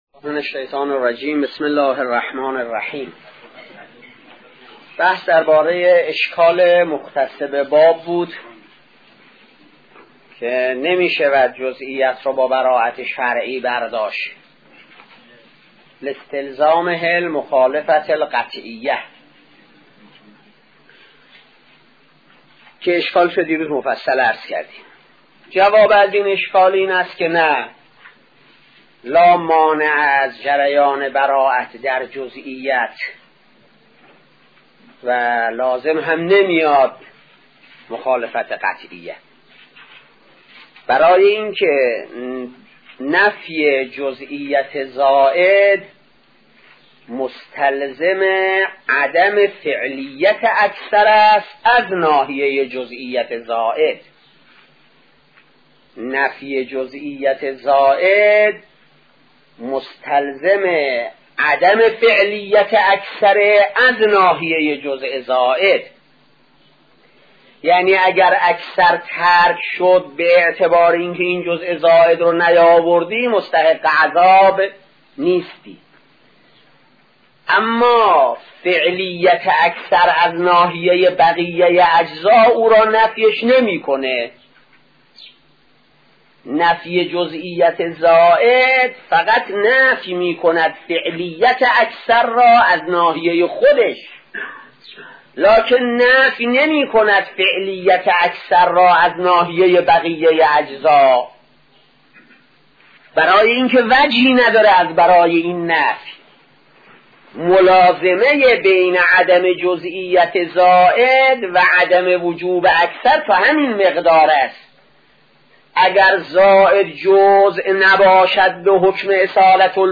آيت الله صانعي - خارج اصول 1 | مرجع دانلود دروس صوتی حوزه علمیه دفتر تبلیغات اسلامی قم- بیان